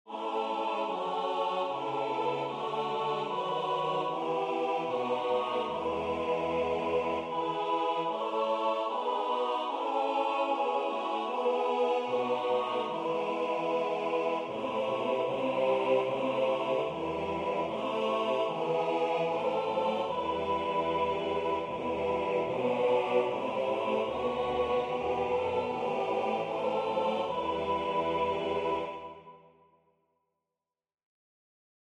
4-part Negative Harmony Example 2 – Ich Dank’ Dir, Lieber Herre.